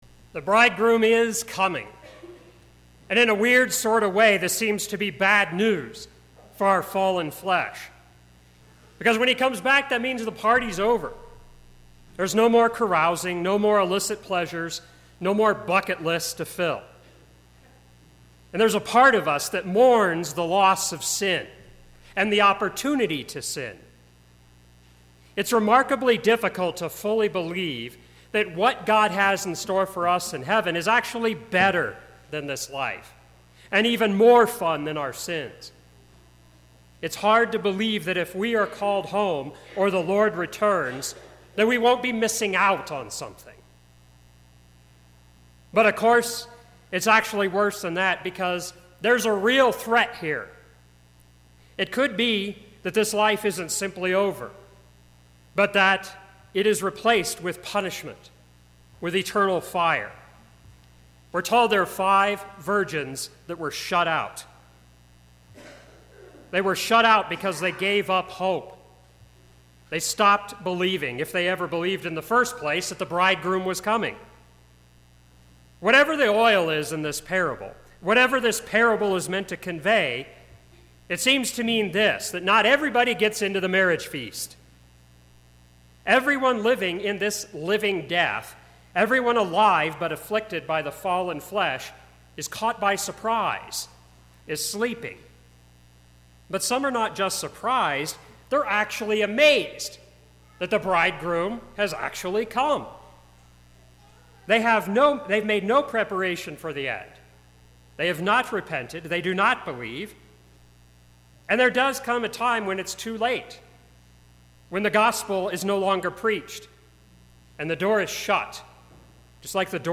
Sermon - 11/26/2017 - Wheat Ridge Lutheran Church, Wheat Ridge, Colorado
Last Sunday of the Church Year